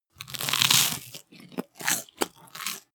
Royalty free sounds: Eat and drink